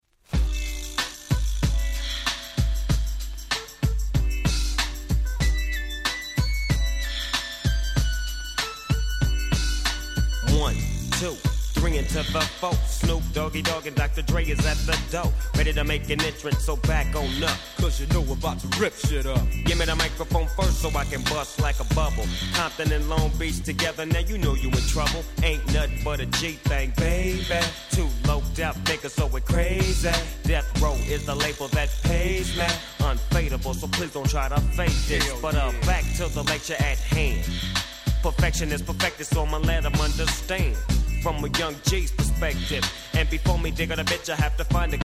【Media】Vinyl 12'' Single
※試聴ファイルは別の盤から録音してございます。
問答無用のWest Coast Hip Hop Classics !!